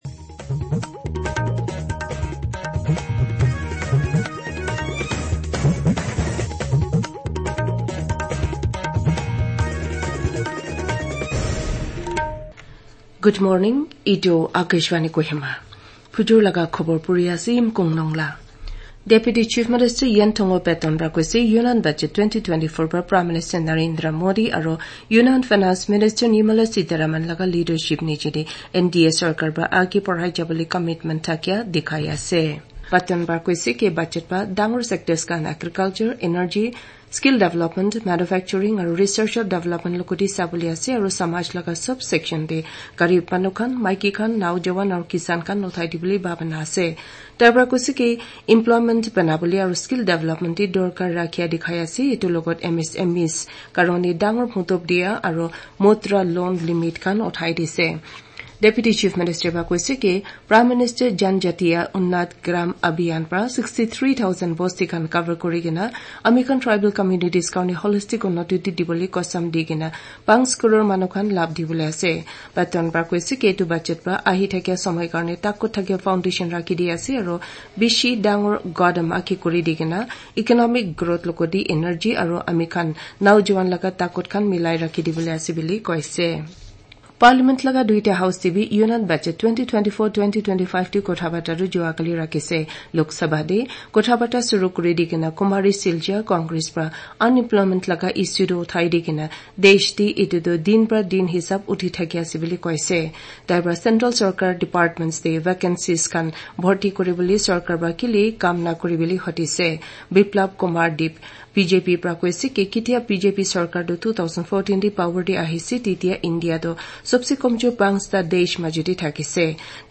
RNU-kohima-Bulletin-Moring-Audio-Nagamese-News-0725.mp3